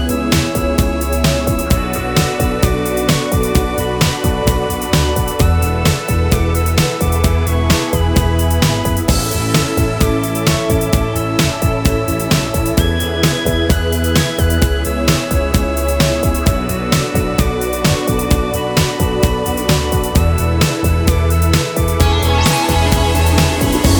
no Backing Vocals Indie / Alternative 3:39 Buy £1.50